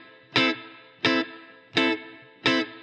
DD_TeleChop_85-Amin.wav